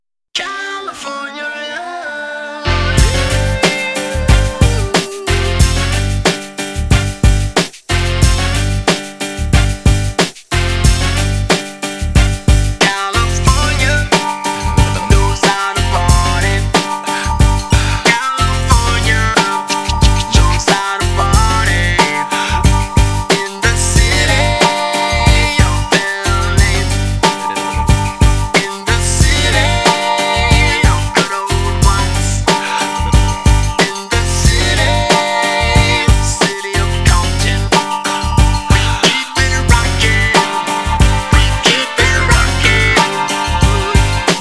90-е
Gangsta rap
G-funk
Westcoast